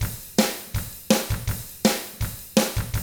164ROCK T6-L.wav